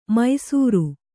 ♪ maisūru